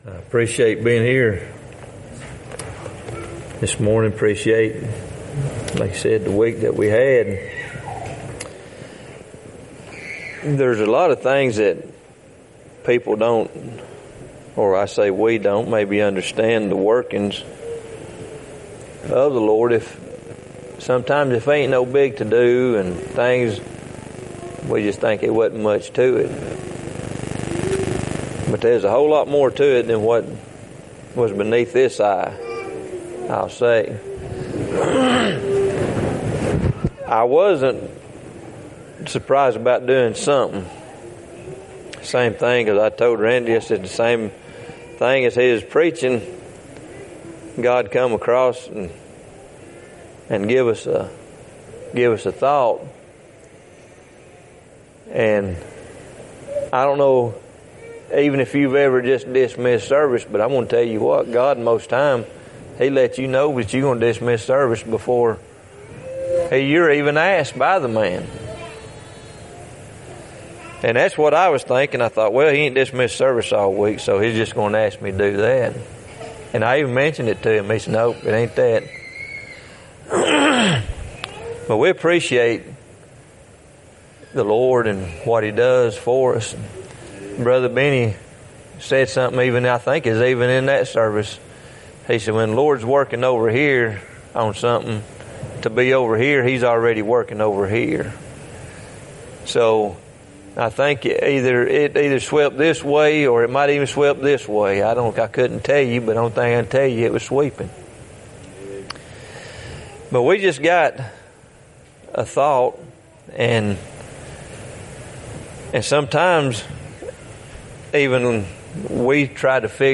2Kings2:19-22 Matthew 5:13-14 Service Type: Wednesday night Having a battle plan.